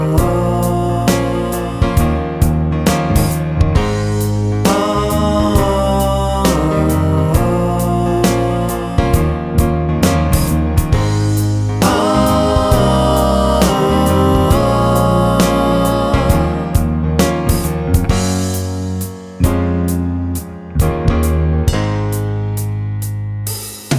No Guitar Solo Or Licks Soul / Motown 4:28 Buy £1.50